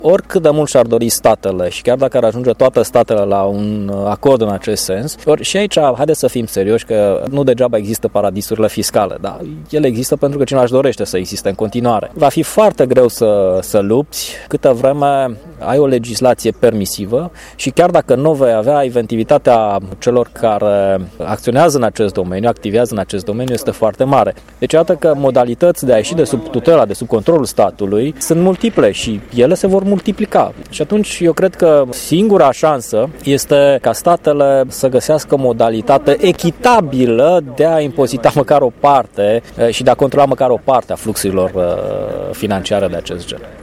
Evenimentul a fost găzduit de Facultatea de Stiinte Economice, Juridice si Administrative a Universității ”Petru Maior” din Tîrgu-Mureș.